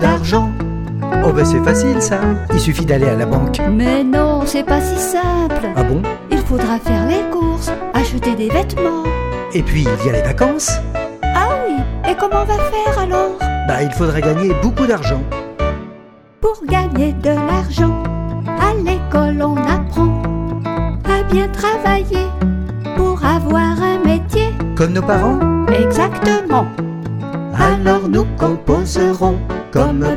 Musicien. Ens. voc. & instr.